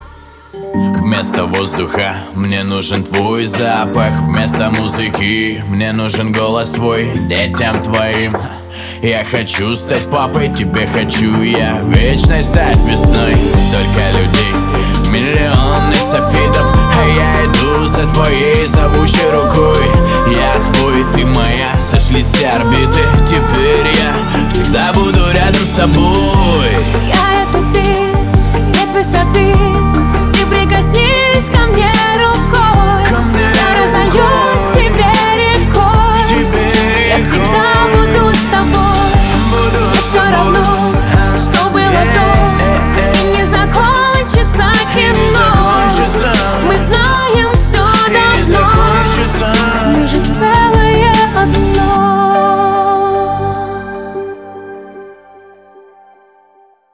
• Качество: 320, Stereo
мужской вокал
женский вокал
Хип-хоп
русский рэп
дуэт
лиричные